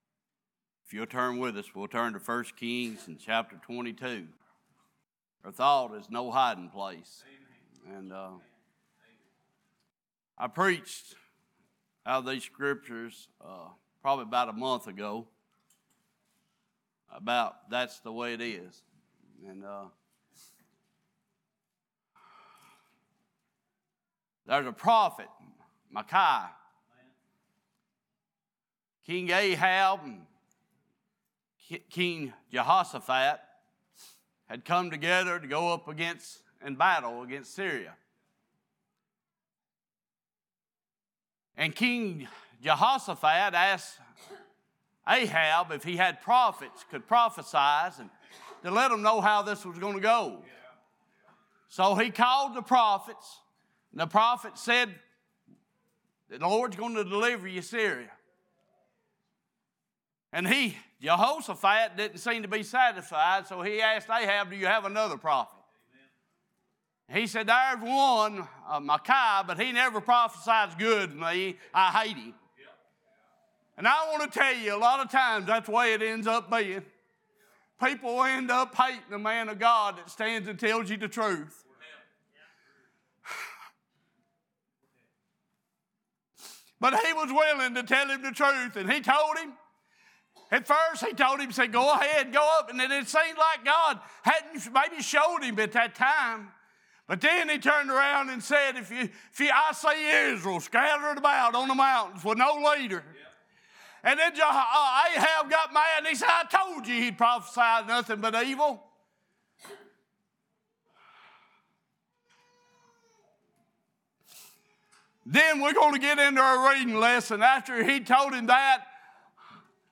Series: Revival Meeting
Genesis 3:4-12 Service Type: Worship « Why Do We Not Fear The Lord?